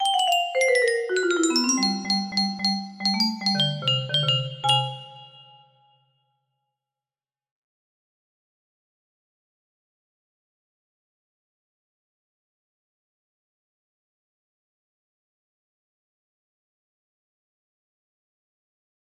Unknown Artist - test2 music box melody